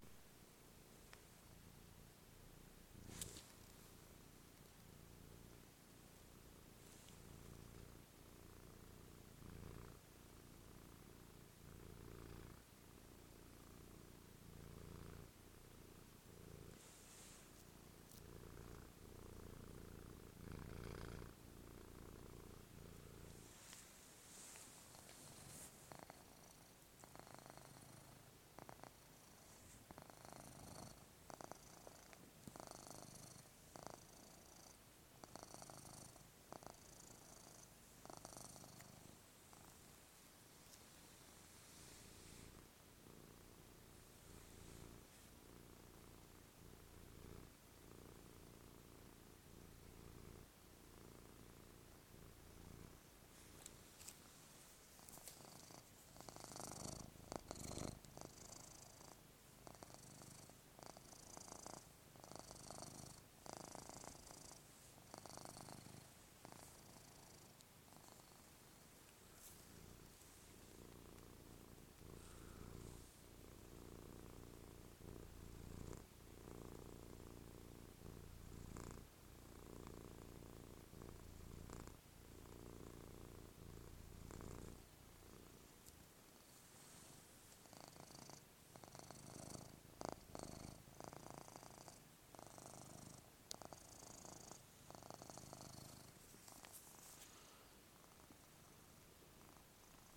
Wettschnurren Oscar und Puschi